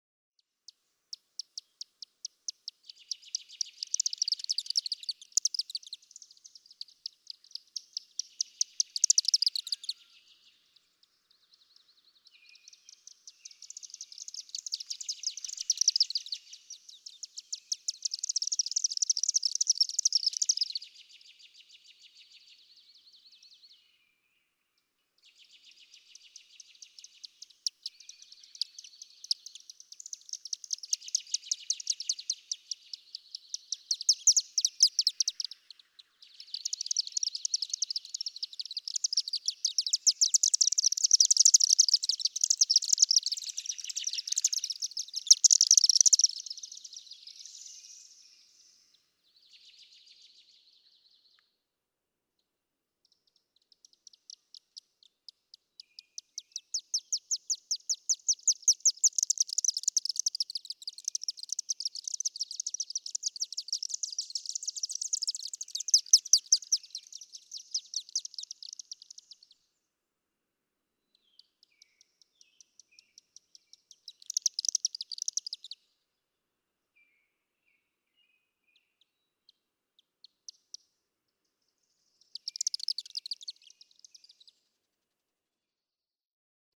Chimney swift
Twitter calls from overhead birds, at twilight, before they head to roost in the chimney.
Mountain Lake, Virginia.
032_Chimney_Swift.mp3